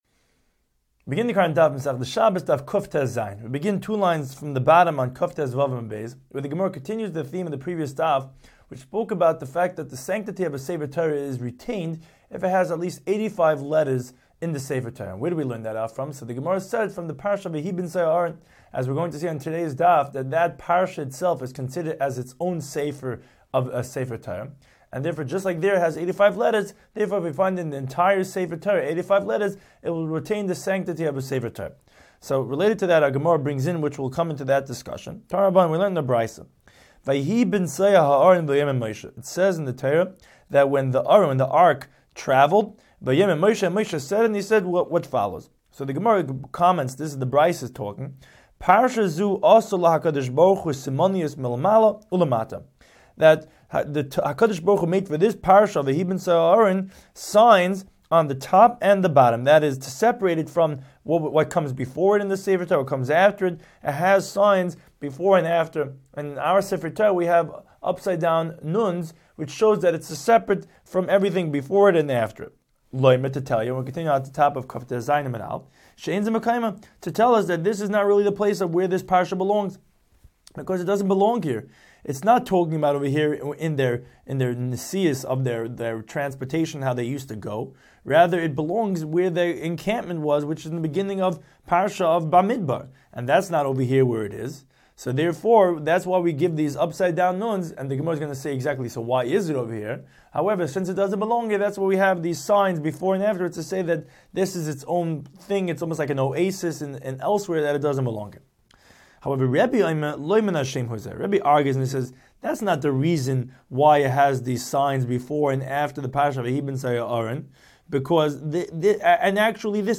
Daf Hachaim Shiur for Shabbos 116